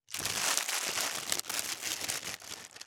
2025年3月1日 / 最終更新日時 : 2025年3月1日 cross 効果音
613コンビニ袋,ゴミ袋,スーパーの袋,袋,買い出しの音,ゴミ出しの音,袋を運ぶ音,